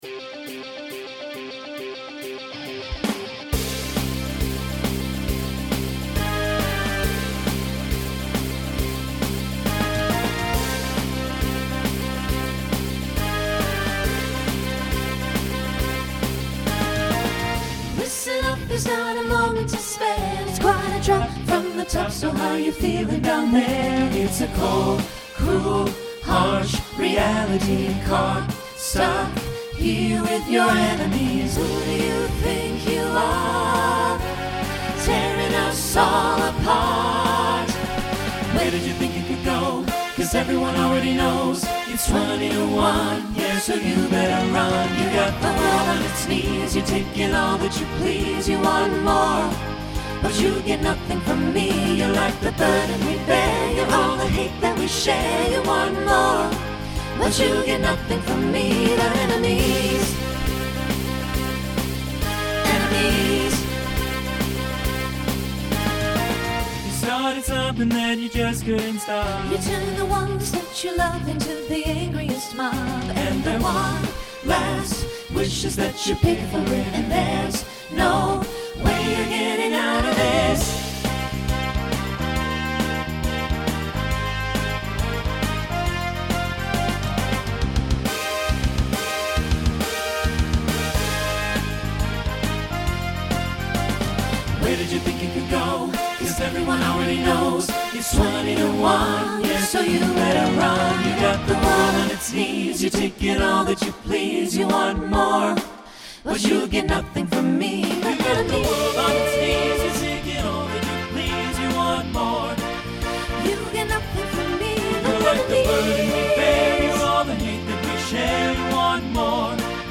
Voicing SATB Instrumental combo Genre Rock